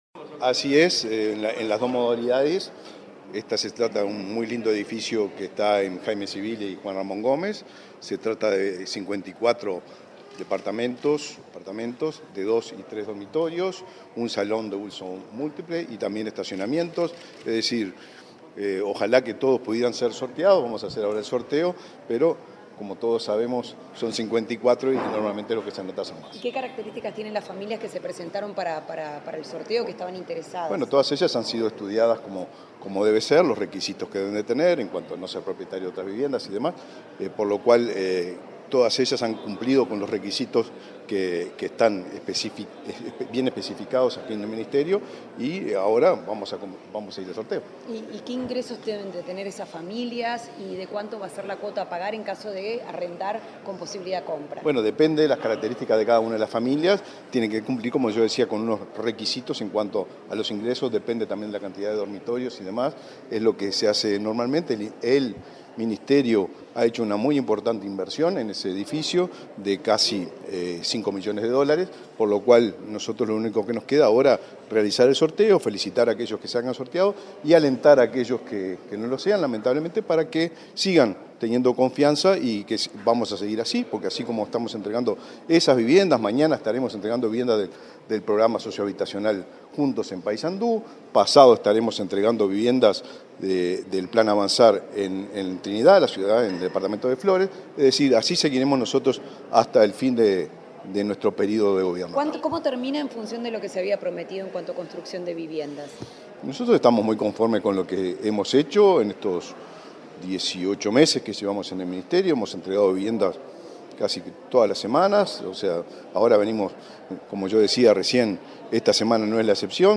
Declaraciones del ministro de Vivienda, Raúl Lozano
El ministro de Vivienda, Raúl Lozano, dialogó con la prensa, luego de participar del sorteo de viviendas entre inscriptos del edificio Jaime Cibils y